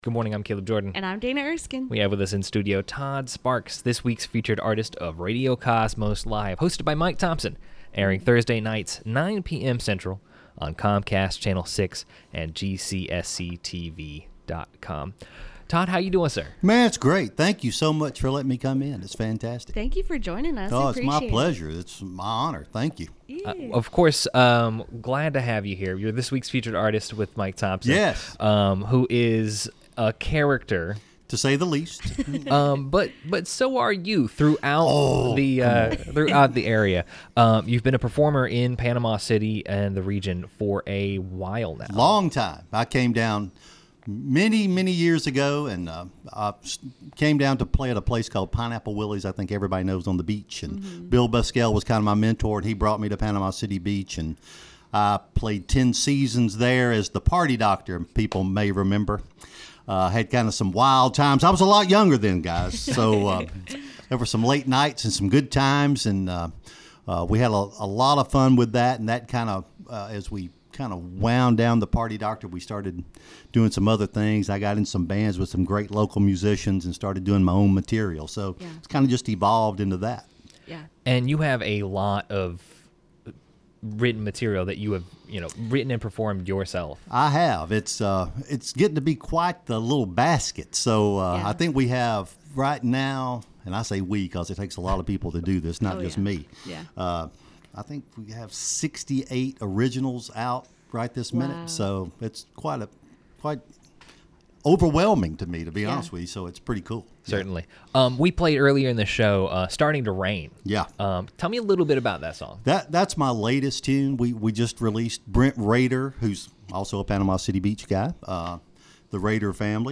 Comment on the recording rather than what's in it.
performs a live cut